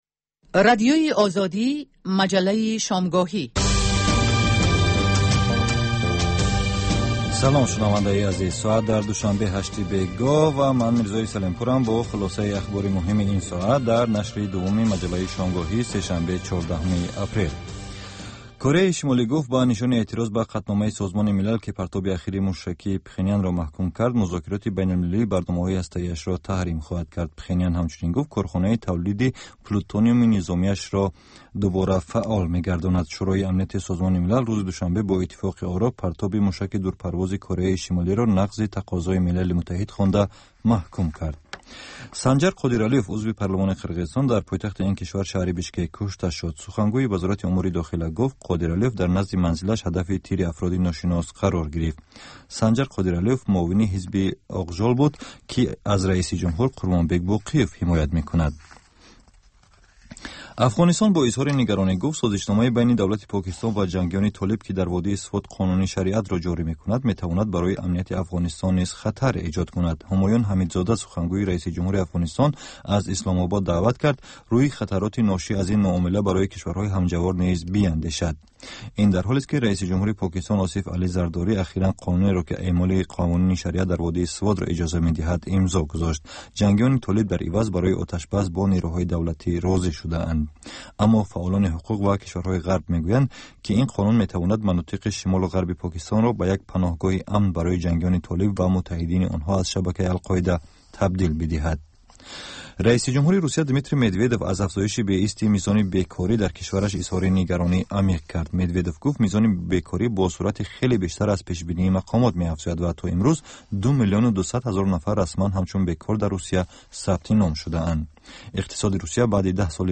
Дар маҷаллаи Дунёи иқтисод коршиносон, масъулони давлатӣ ва намояндагони созмонҳои марбутаи ғайридавлатию байналмилалӣ таҳаввулоти ахири иқтисоди кишварро баррасӣ мекунанд.